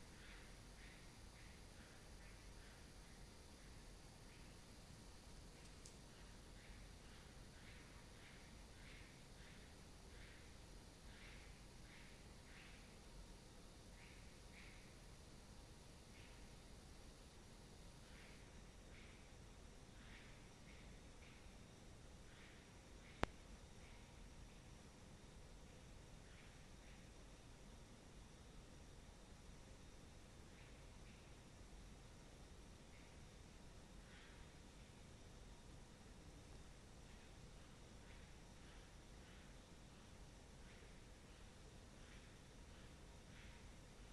Grinding teeth